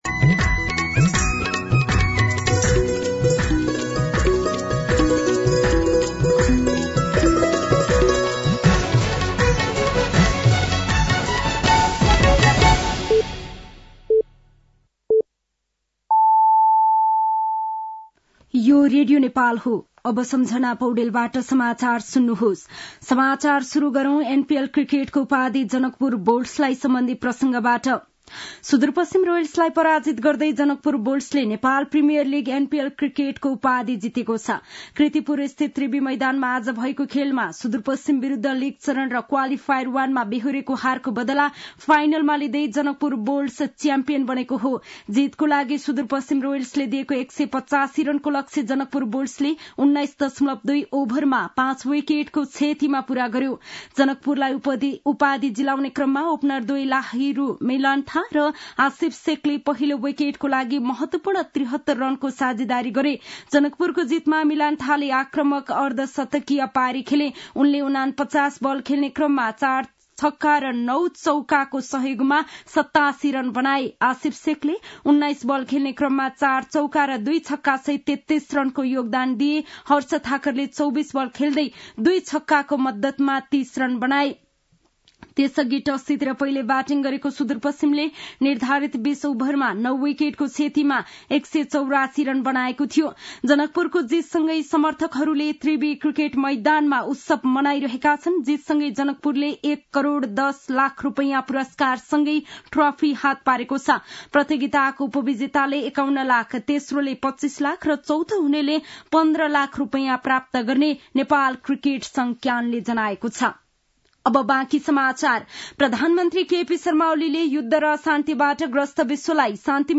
साँझ ५ बजेको नेपाली समाचार : ७ पुष , २०८१
5-PM-Nepali-News-9-6.mp3